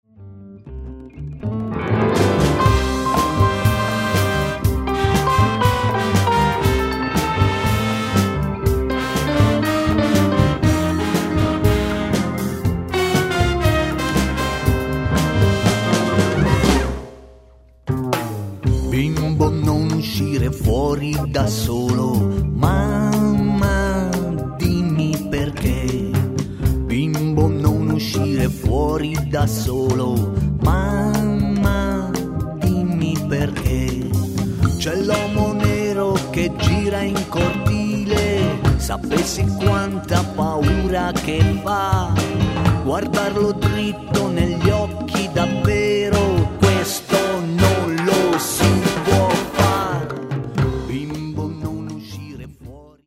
violino
batteria
percussioni
sax ed arrangiamento fiati
trombone
tromba